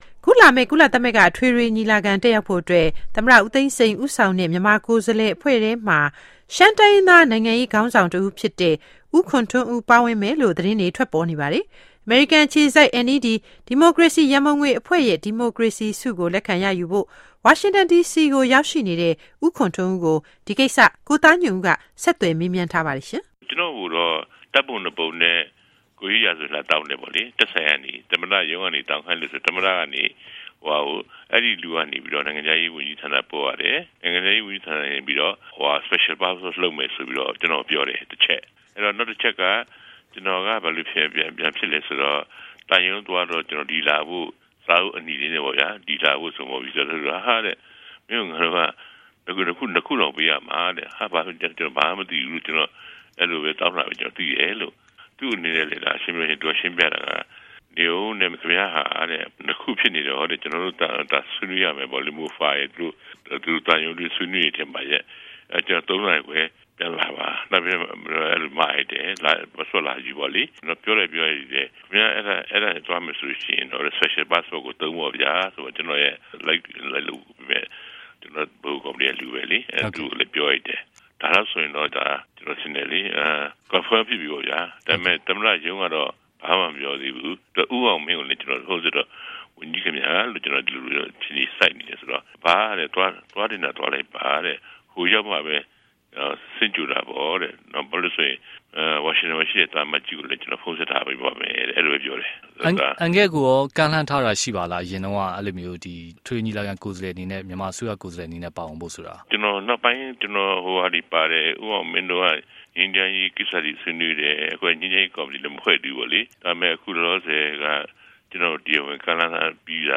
Q&A Khun Htun Oo